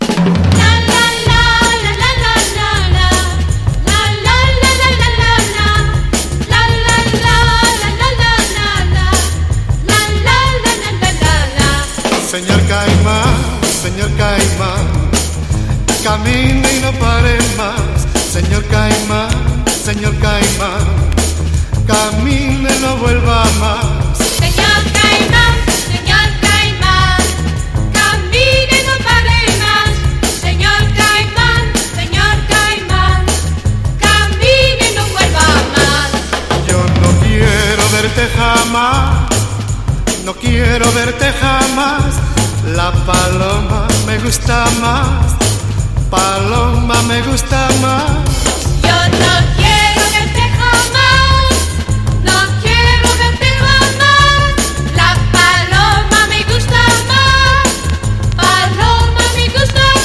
NEW WAVE / 80'S / SYNTH POP
アンニュイかつエレガントな表情が抜群に洒落てます！